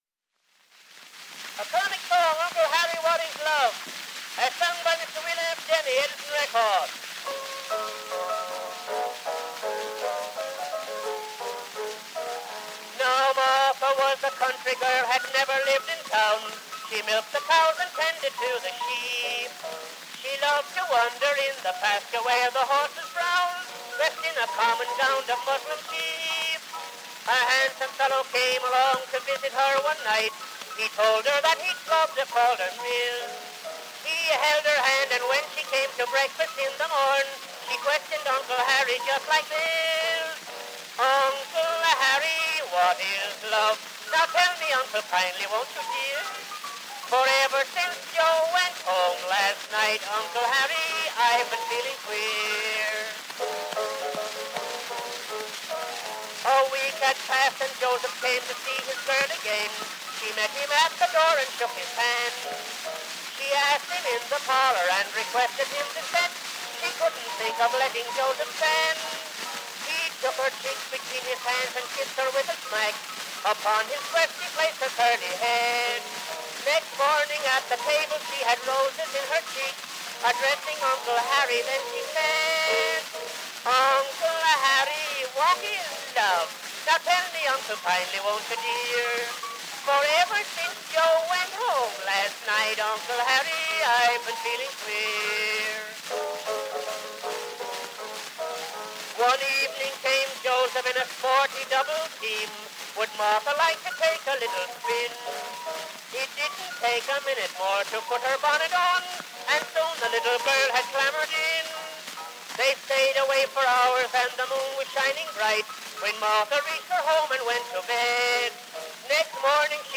Popular music